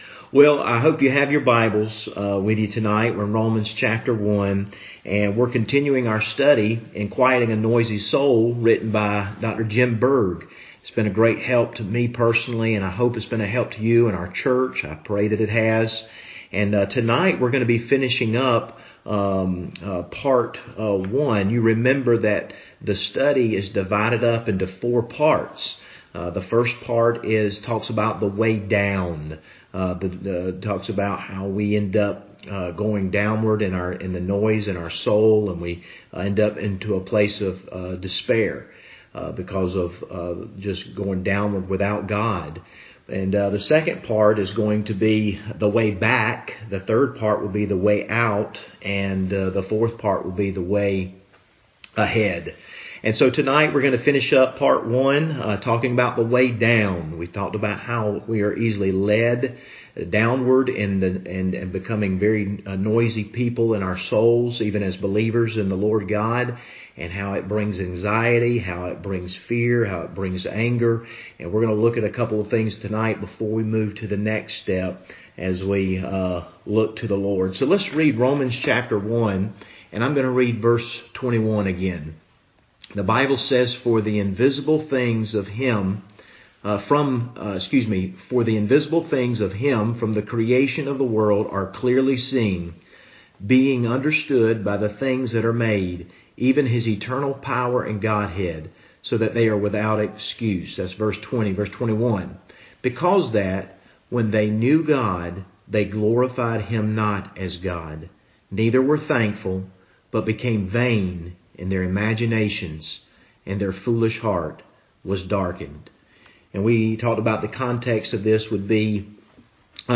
Service Type: Wednesday Evening Topics: anger , anxiety , despair , unbelief